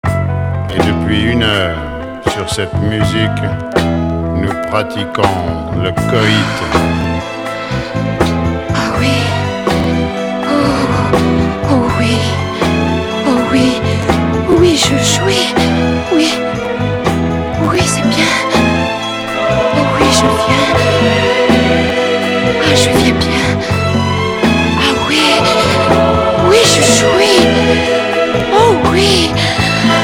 Erotico pop